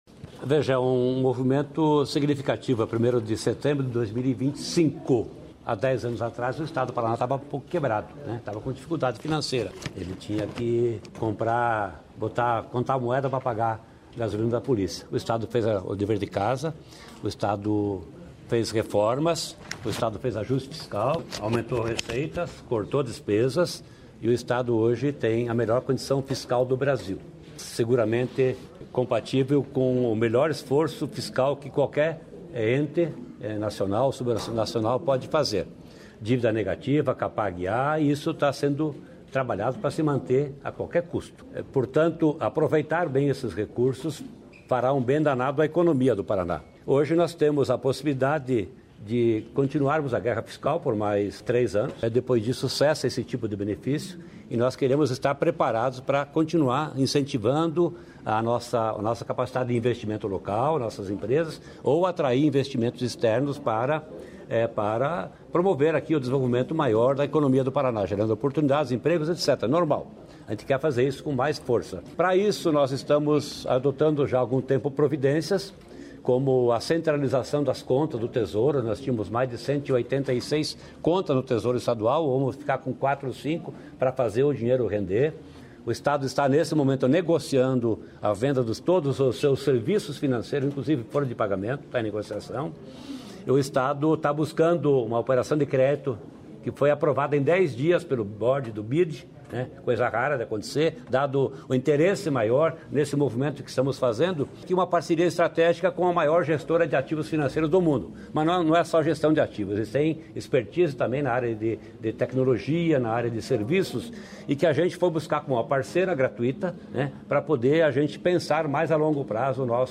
Sonora do secretário estadual da Fazenda, Norberto Ortigara, sobre parceria para buscar investimentos internacionais